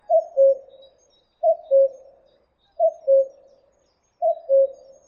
kuckuck.mp3